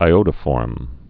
(ī-ōdə-fôrm, ī-ŏdə-)